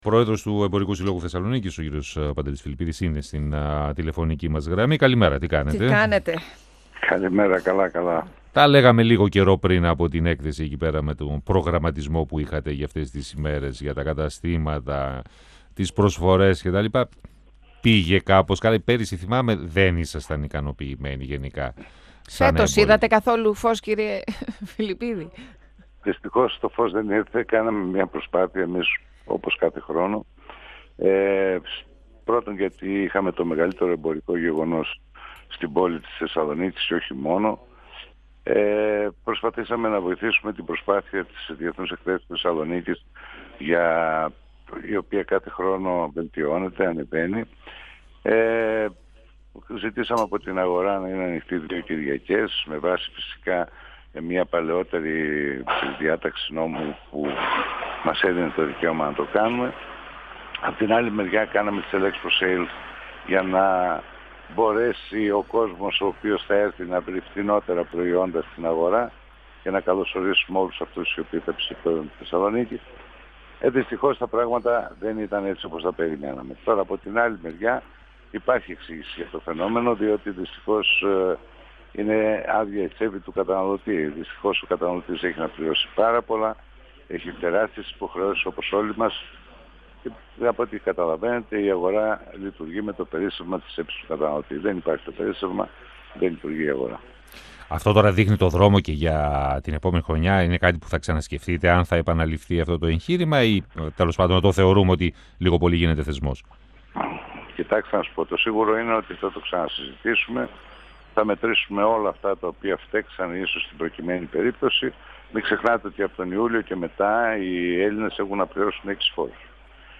στον 102FM του Ρ.Σ.Μ. της ΕΡΤ3